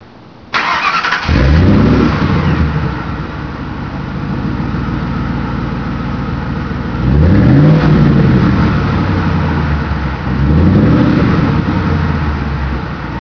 Clicking on the image will let you hear what the exhaust sounds like.
Now my performance truck screams (figuratively and literally) the way it should.
Exhaust.wav